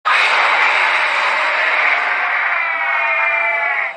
File:Deafrog roar.ogg